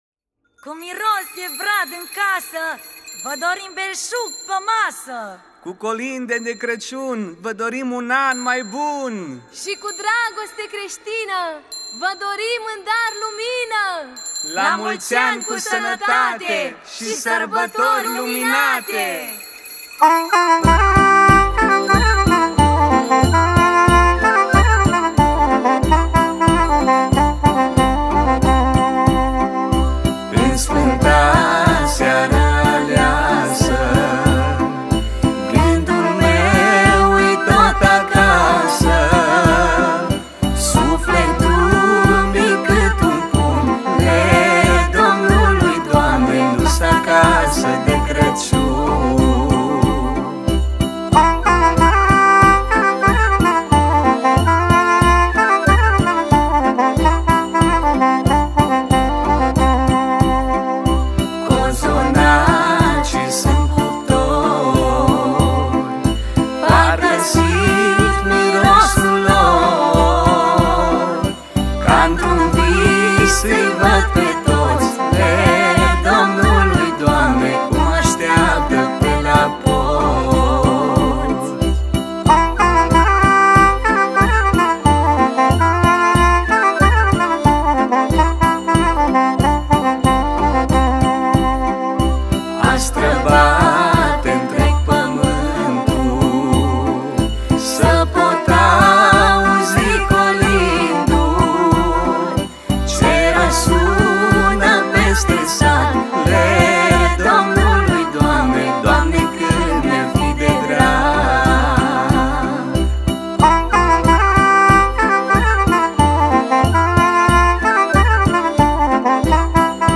Colinde de Craciun